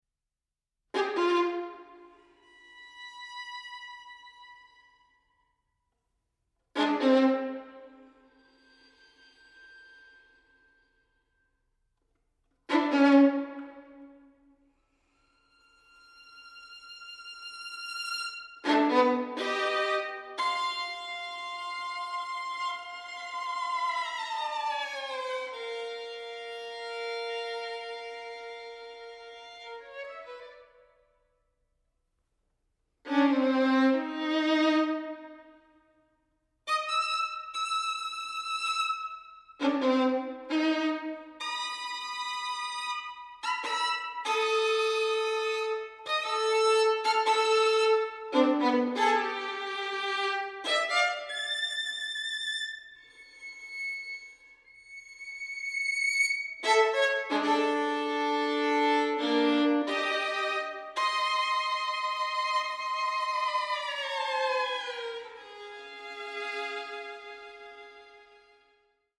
• Genres: Classical, Opera, Chamber Music
Recorded at Evelyn & Mo Ostin Music Center